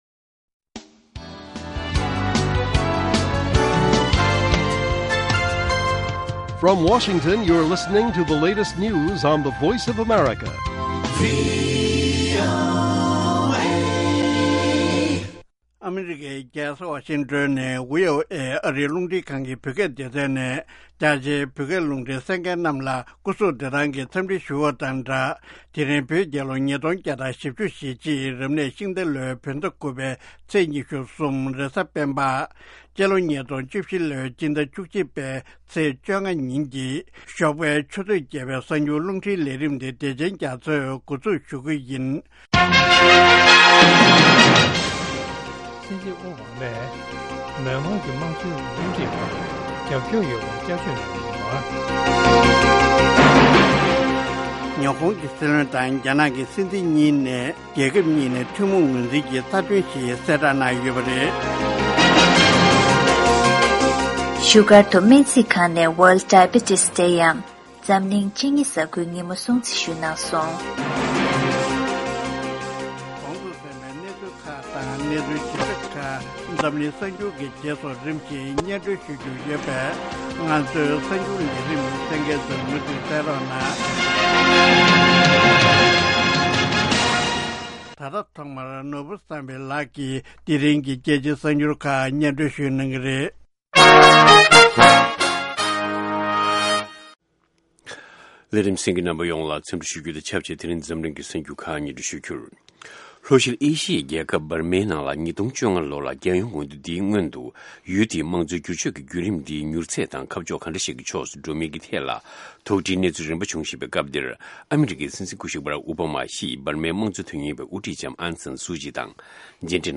སྔ་དྲོའི་གསར་འགྱུར།